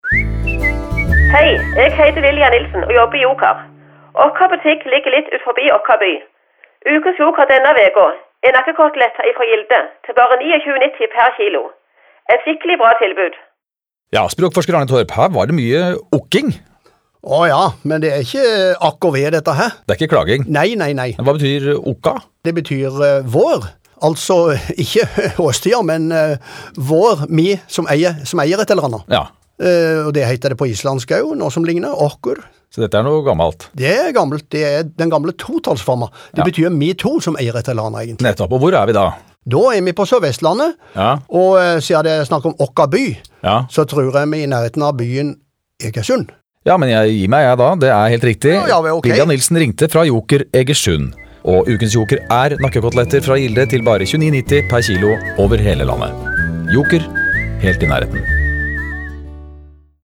17. Hvilken dialekt hører man i denne radioreklamen fra Joker? (Stopp avspillingen når svardelen kommer.)
Joker-Egersund.mp3